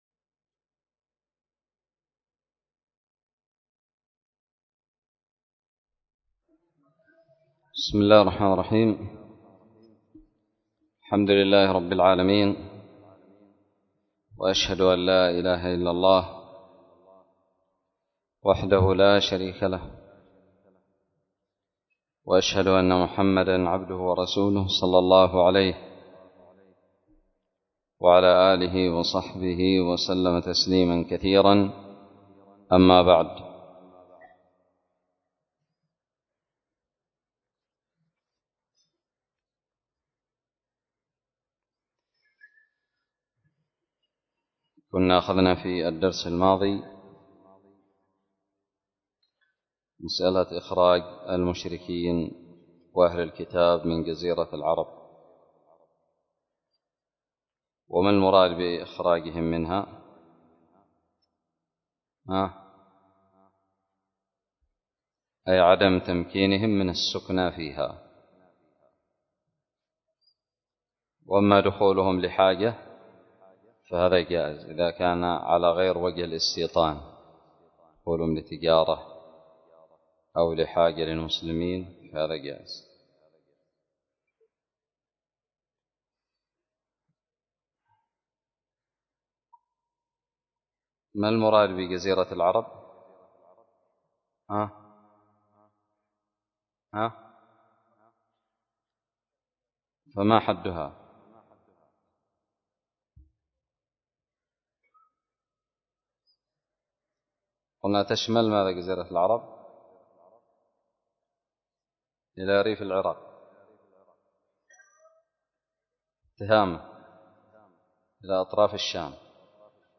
الدرس الخامس والأربعون من كتاب الجهاد من الدراري
ألقيت بدار الحديث السلفية للعلوم الشرعية بالضالع